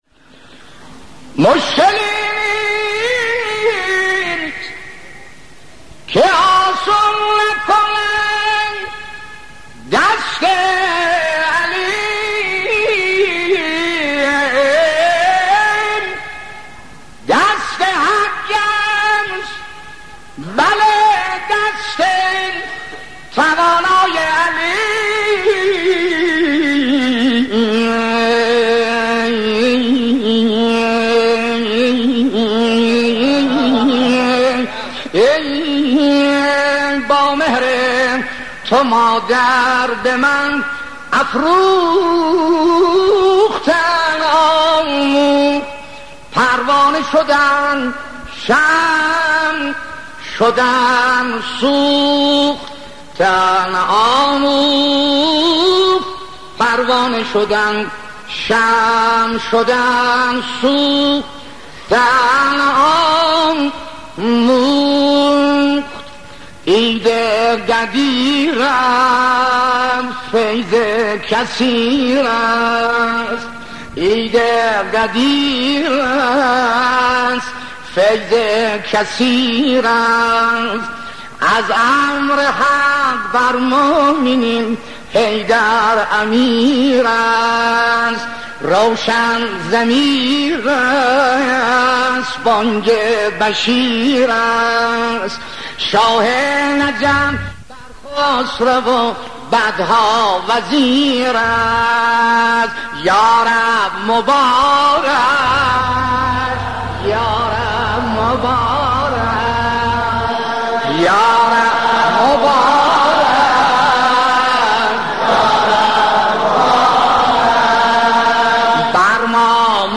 صوت | مولودی‌خوانی «مؤذن‌زاده اردبیلی» ویژه عید غدیر
نوای ماندگار و شنیدنی مرحوم سلیم مؤذن‌زاده اردبیلی را به مناسبت عید سعید غدیر می‌شنویم.